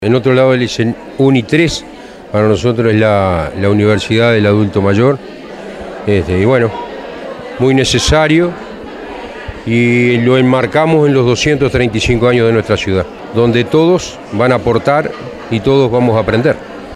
alcalde_de_pando_alcides_perez_0.mp3